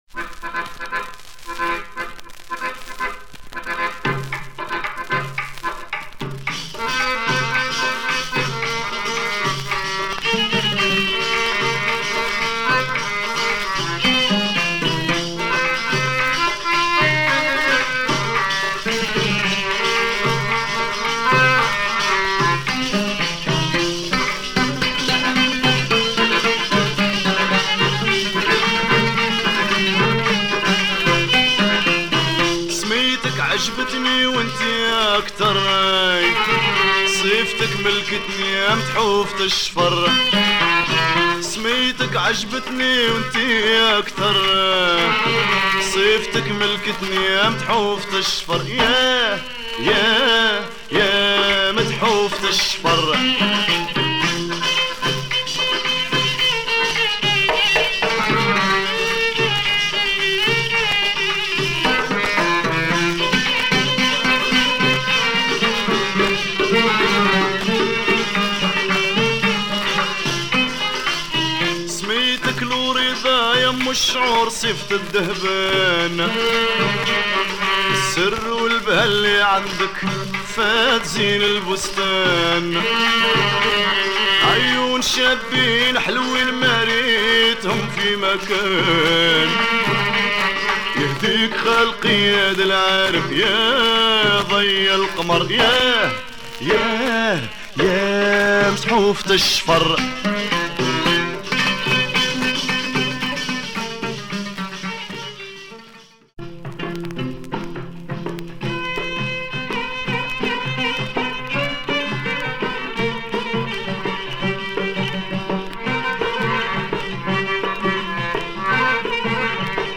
Algerian singer
trumpet